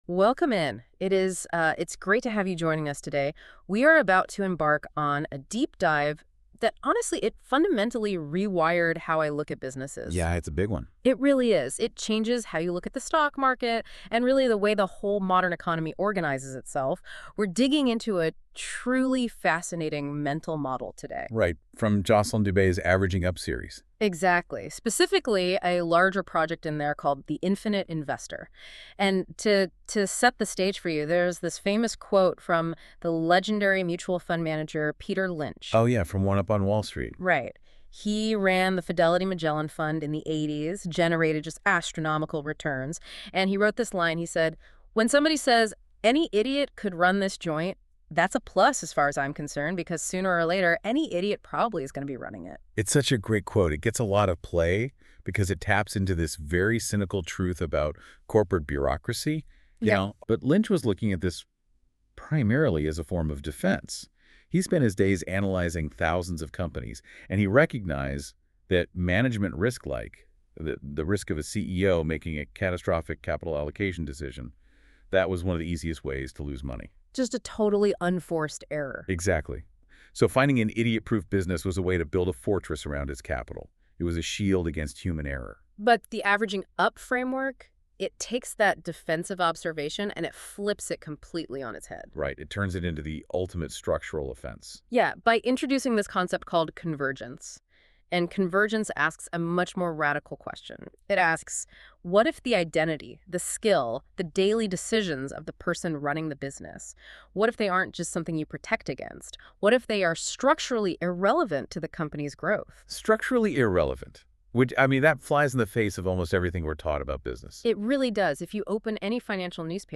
Listen to the deep-dive discussion – How Convergent Businesses Grow for Free.